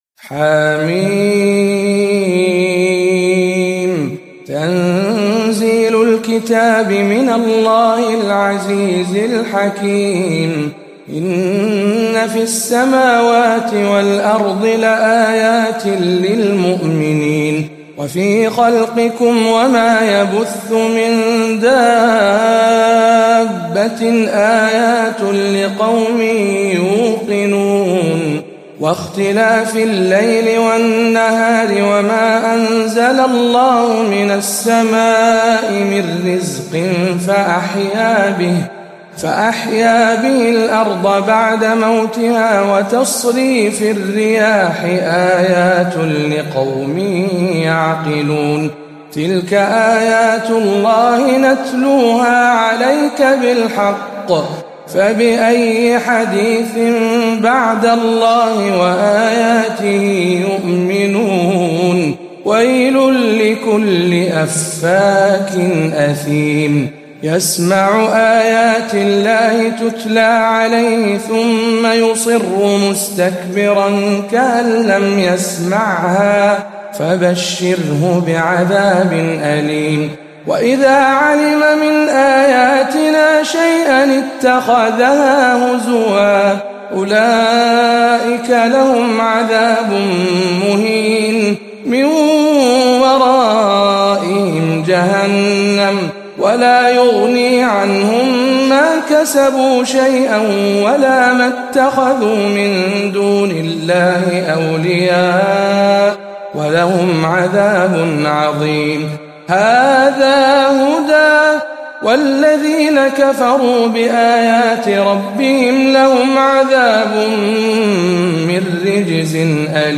سورة الجاثية بجامع عمر بن الخطاب بمكة المكرمة - رمضان 1439